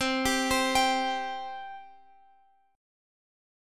C 5th